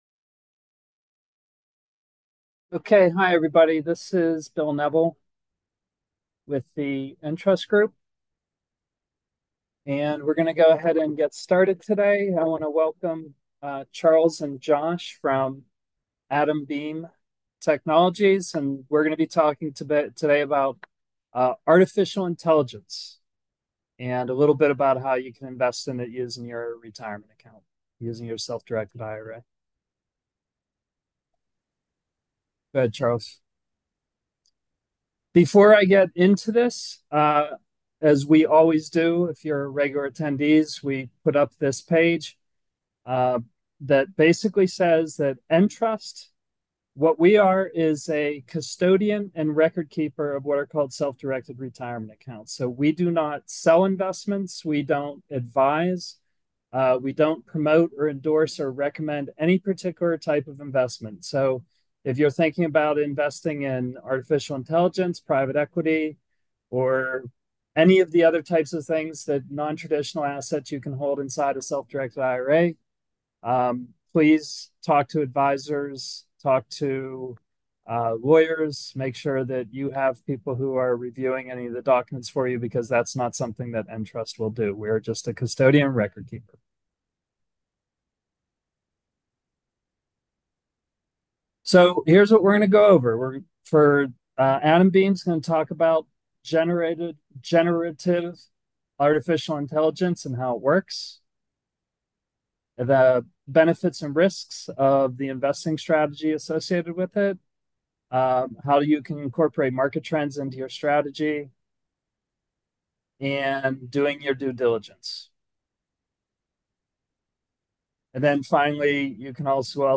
Presentation Audio: Stream or download the mp3 here.Presentation Timestamps: Section 1: A Brief History on AI • Starts: 10:10 • Ends: 12:15 Section 2: What is Generative AI?